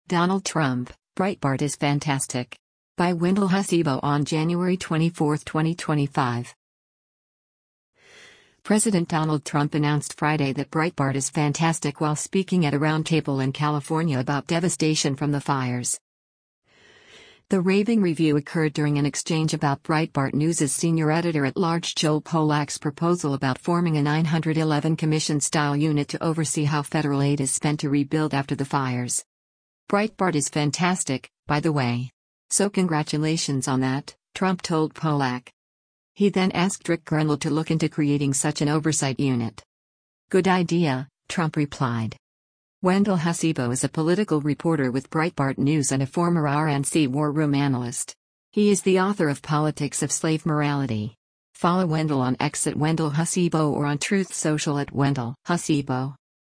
President Donald Trump announced Friday that “Breitbart is fantastic” while speaking at a roundtable in California about devastation from the fires.